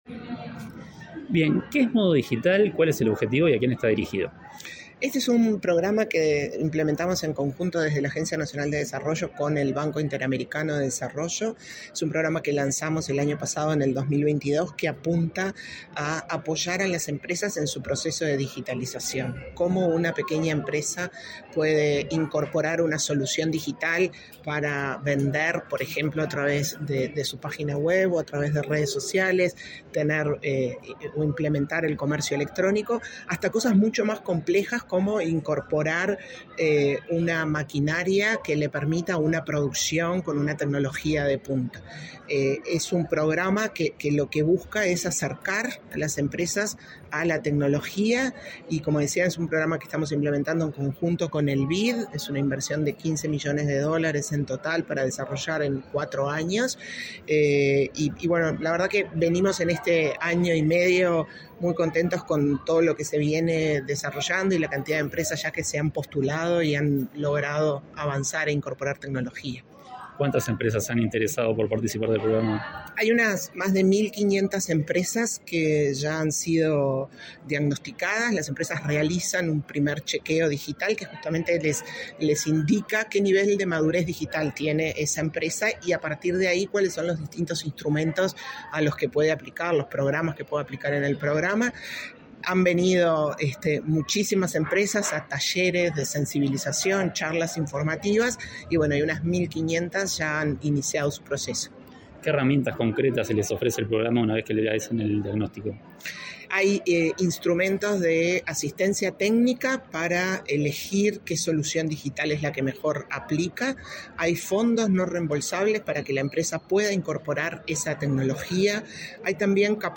Entrevista a la presidenta de la ANDE, Carmen Sánchez
Entrevista a la presidenta de la ANDE, Carmen Sánchez 31/07/2023 Compartir Facebook X Copiar enlace WhatsApp LinkedIn La Agencia Nacional de Desarrollo (ANDE) presentó, este 31 de julio, su programa Modo Digital, con el que se promueve que las micro, pequeñas y medianas empresas incorporen tecnología que les permita aumentar su competitividad en el mercado. Tras el evento, Sánchez realizó declaraciones a Comunicación Presidencial.